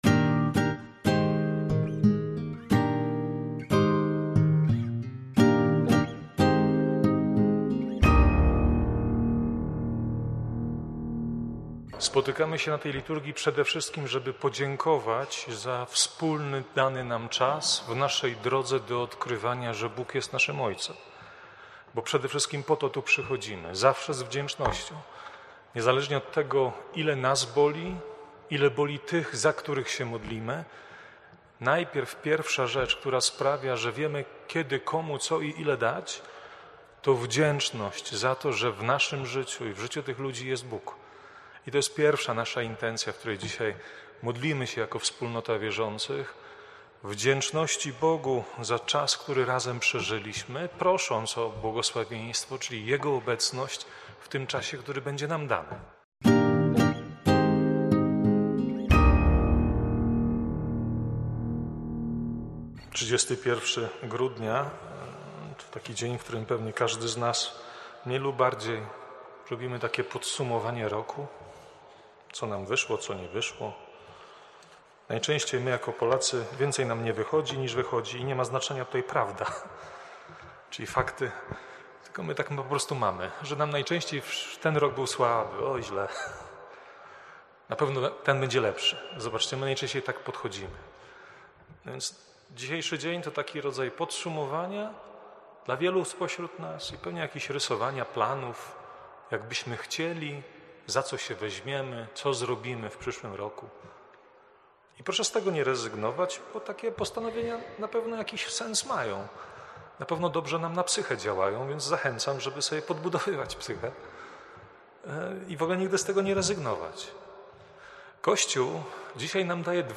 kazania.
wprowadzenie do Liturgii, oraz homilia: